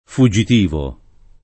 fuggitivo [fuJJit&vo] agg. e s. m. — latinismo ant. fugitivo [